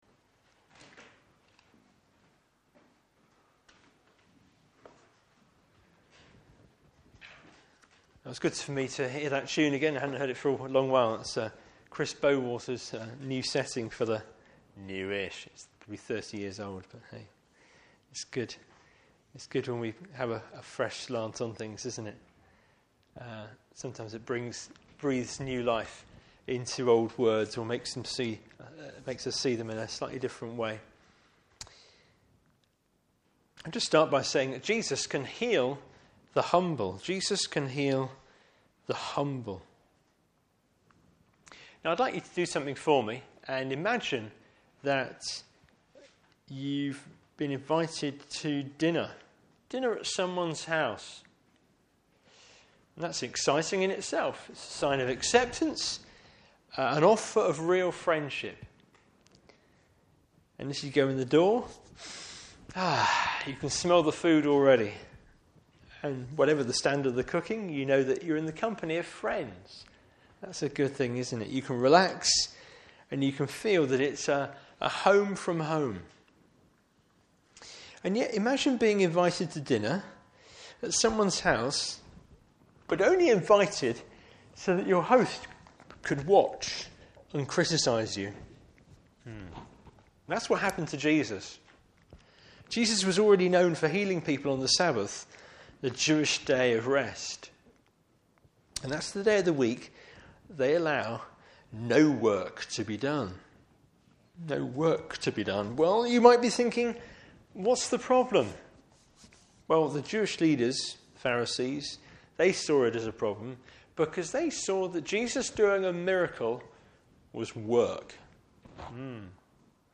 Service Type: Morning Service Bible Text: Luke 14:1-14.